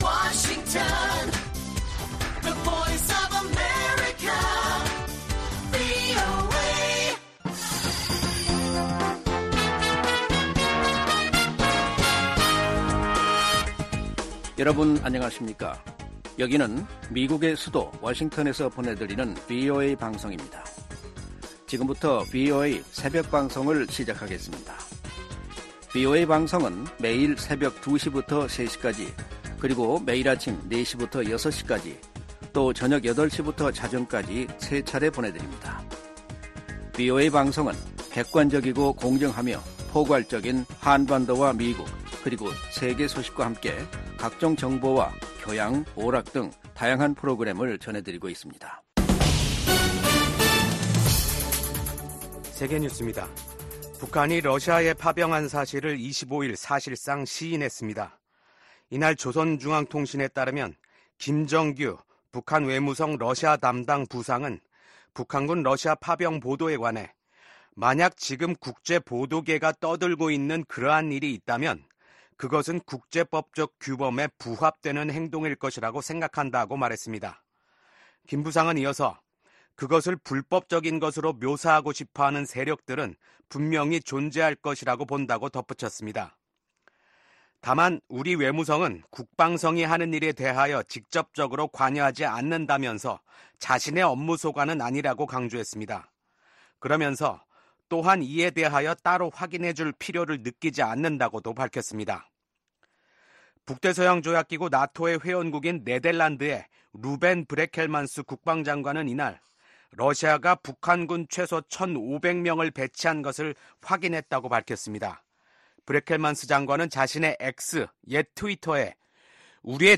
VOA 한국어 '출발 뉴스 쇼', 2024년 10월 26일 방송입니다. 미국 국방부는 러시아에 파병된 북한군이 우크라이나에서 전쟁에 참여할 경우 러시아와 함께 공동 교전국이 될 것이라고 경고했습니다. 미국 하원 정보위원장이 북한군의 러시아 파병과 관련해 강경한 대응을 촉구했습니다.